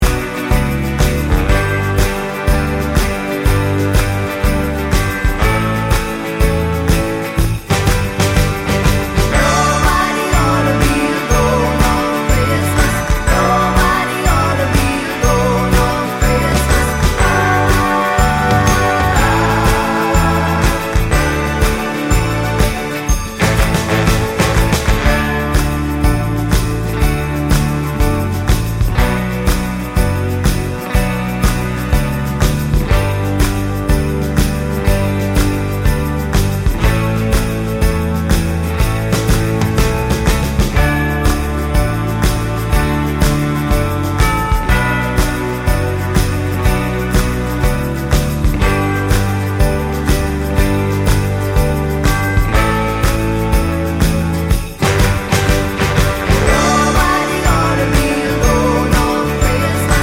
Minus Sax Solos Christmas 4:07 Buy £1.50